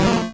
Cri de Ronflex dans Pokémon Rouge et Bleu.